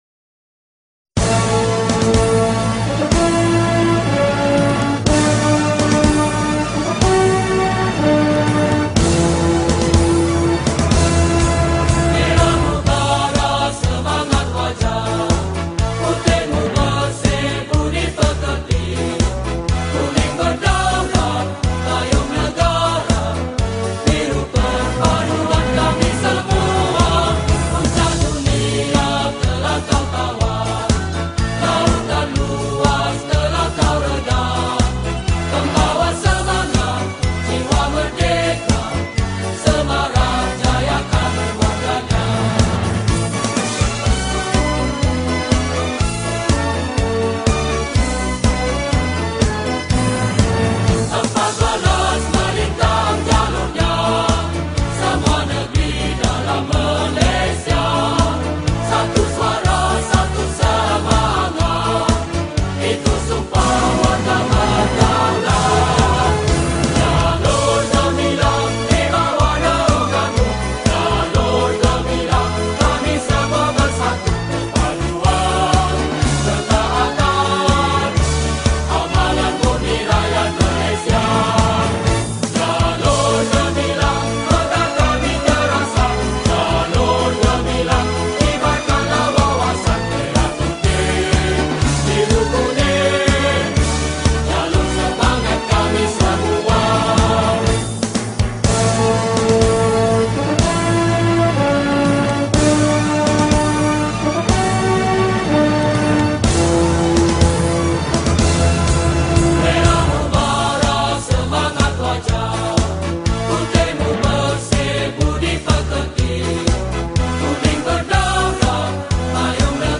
Patriotic Songs
Skor Angklung